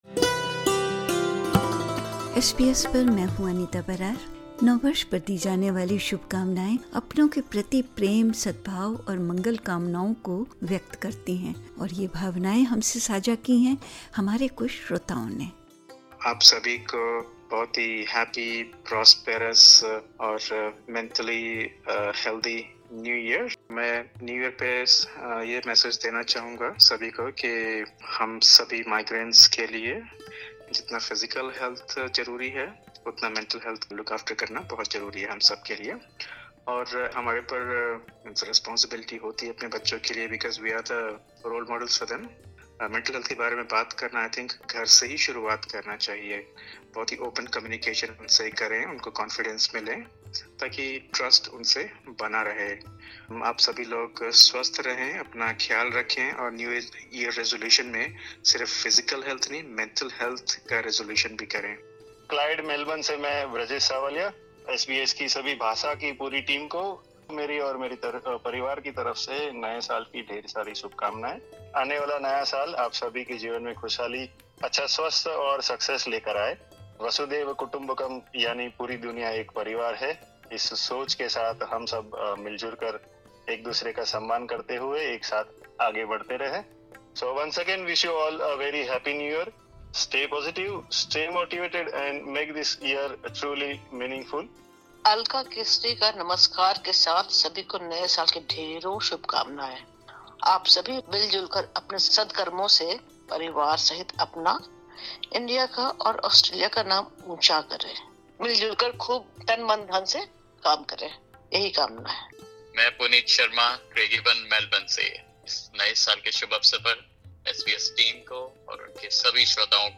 Welcoming 2026 With Hope: Listeners emphasise peace, humanity and mental health